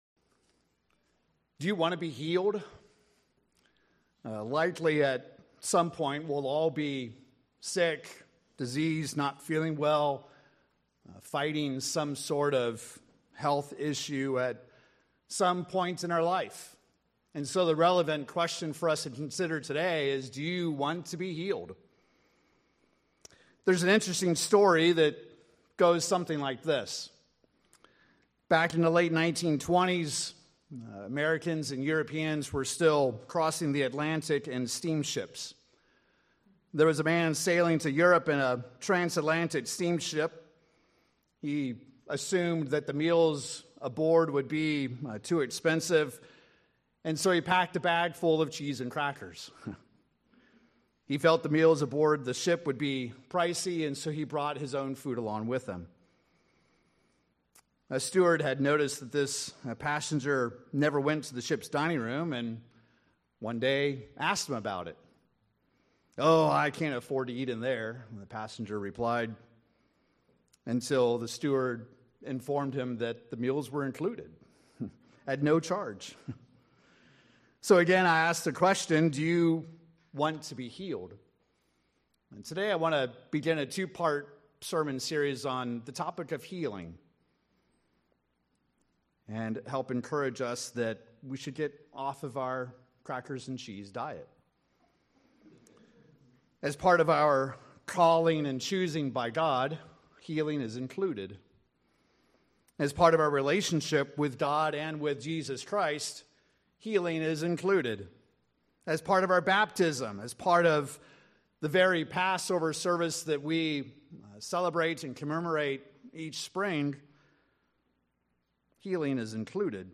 The Bible reveals to us that God promises healing. In the sermon we review this promise, what is required to obtain it, and whether or not it is God's will that you receive this promise.